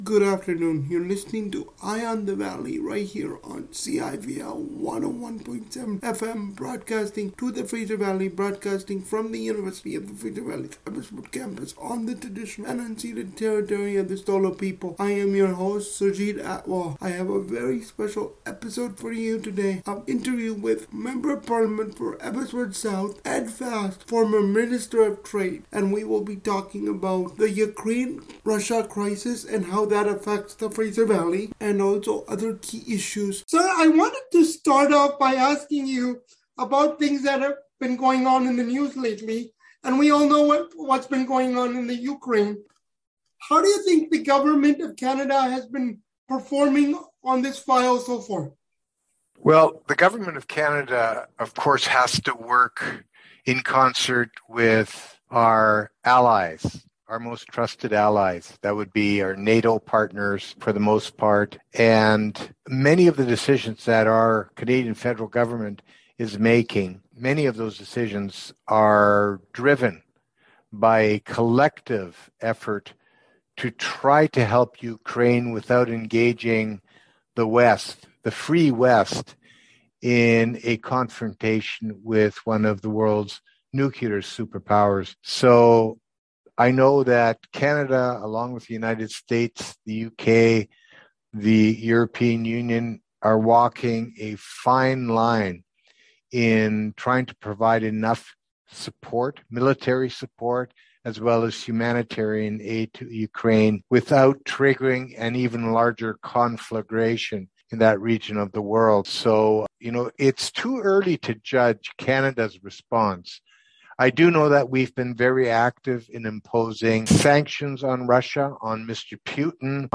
Ed Fast, Member of Parliament from Abbotsford South spoke with CIVL radio on the Ukrainian population present in Abbotsford. Mr. Fast says Abbotsford has the largest population of Mennonites in British Columbia, which have roots in Ukraine. He also says some of the seniors from Ukraine fled the Soviet Union.
Ed-Fast-full-interview-2.mp3